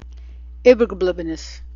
Ibbergerblibbernis: (Ib-ber-ger-blib-er-ness) leftovers.